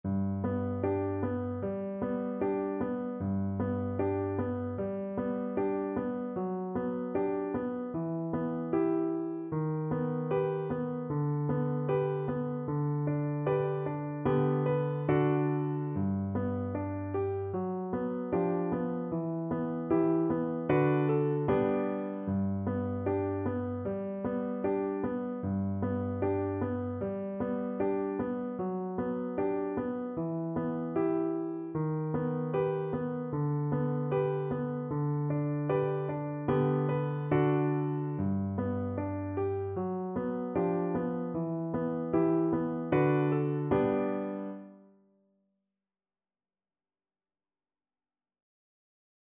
Andante =c.76